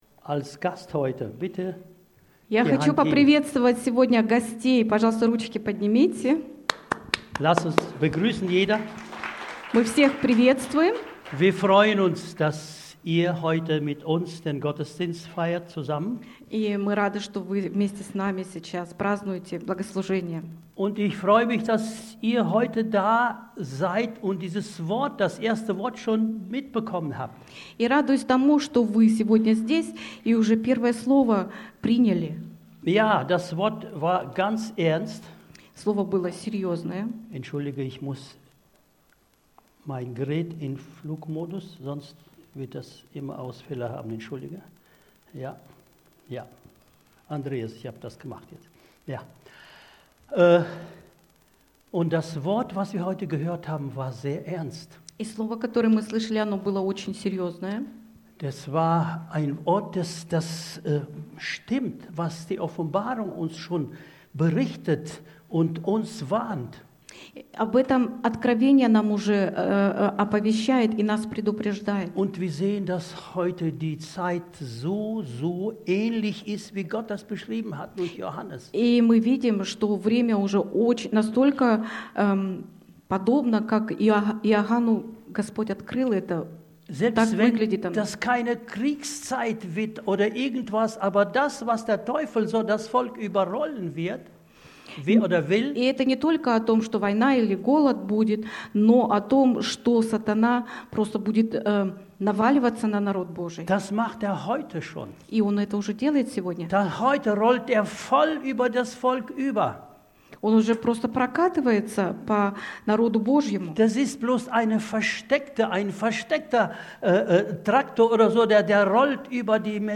Predigten – Freie Evangeliums Christengemeinde Löningen